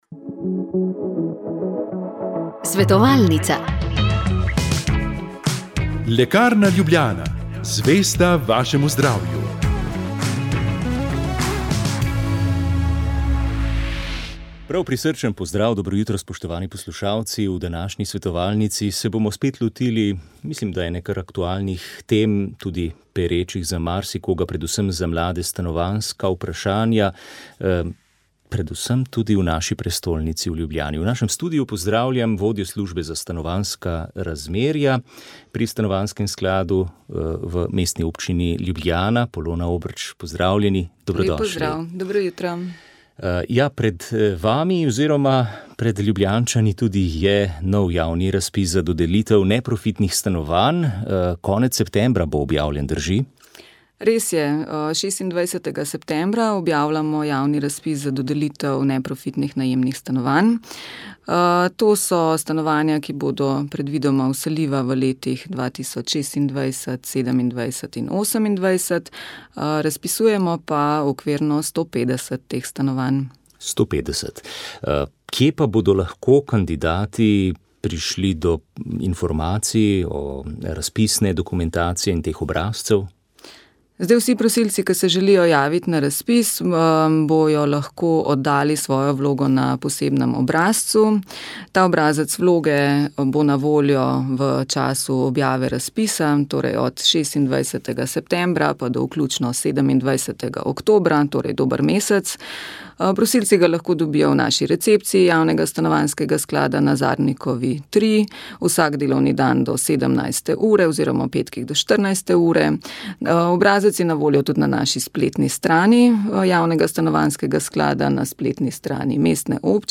Molili so bogoslovci.